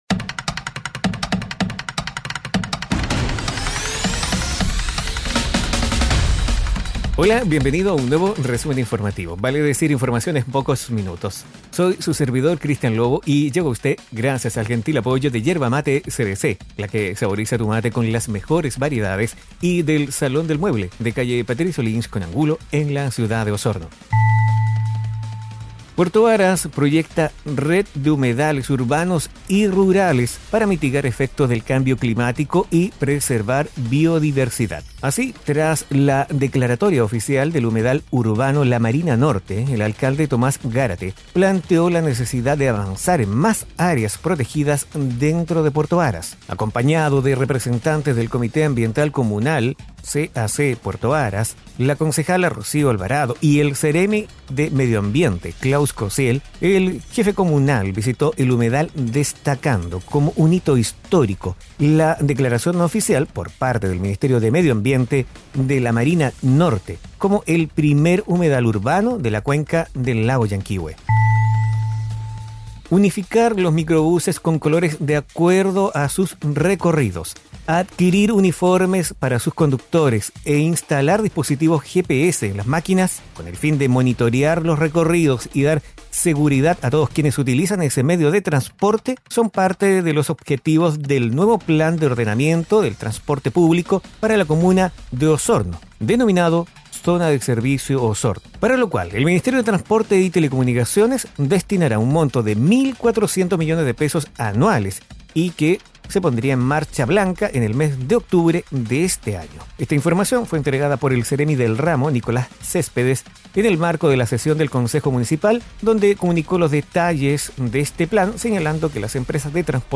Informaciones y noticias enfocadas en la Región de Los Lagos. Difundido en radios asociadas.